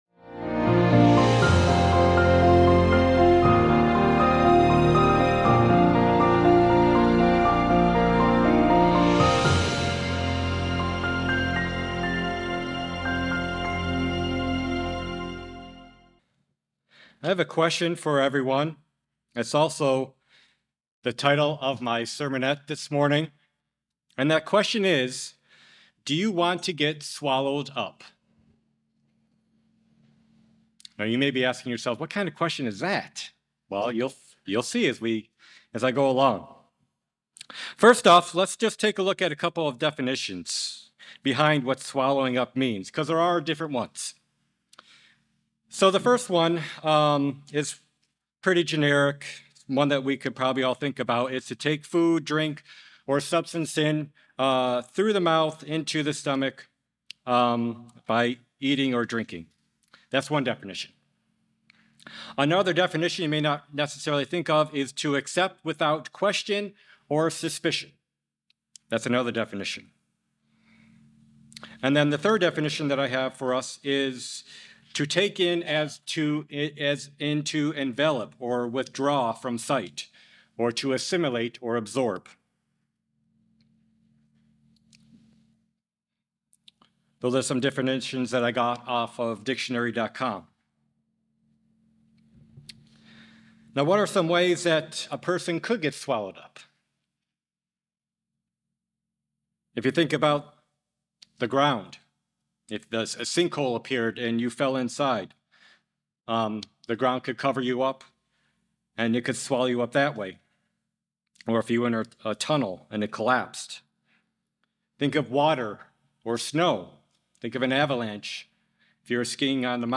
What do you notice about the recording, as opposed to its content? Given in Charlotte, NC Columbia, SC Hickory, NC